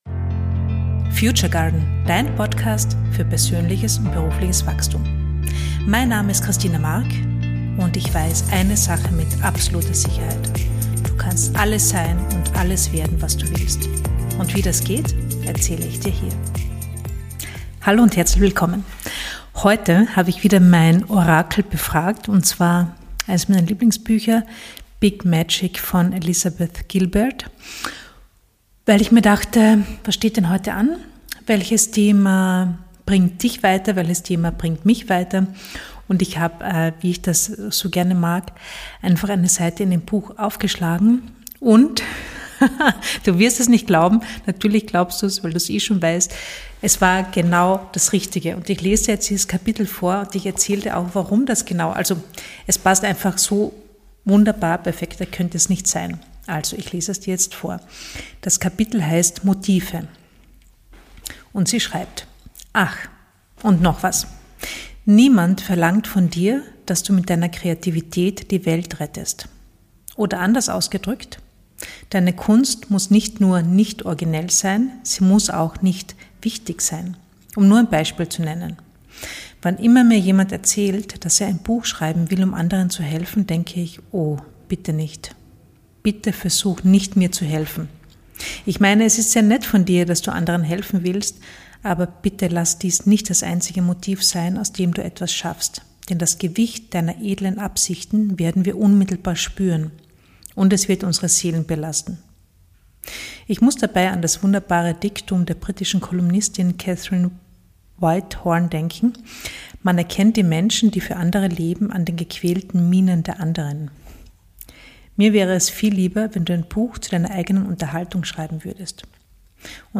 In dieser Episode lese ich dir ein super hilfreiches Kapitel aus dem Buch Big Magic vor.